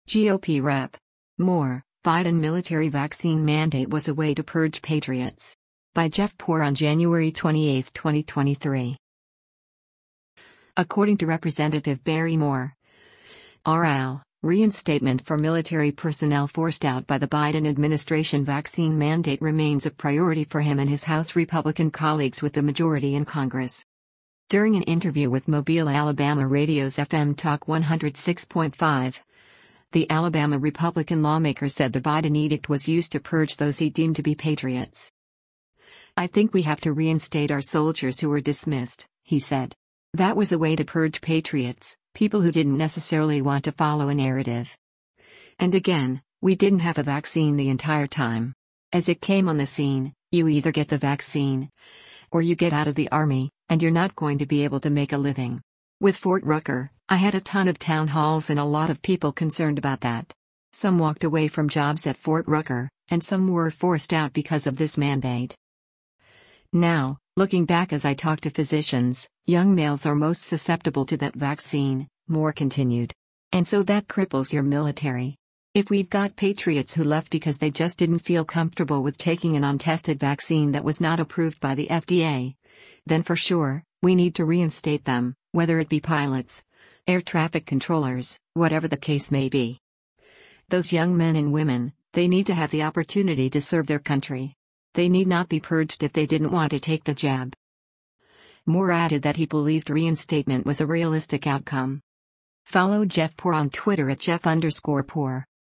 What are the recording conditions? During an interview with Mobile, AL radio’s FM Talk 106.5, the Alabama Republican lawmaker said the Biden edict was used to “purge” those he deemed to be patriots.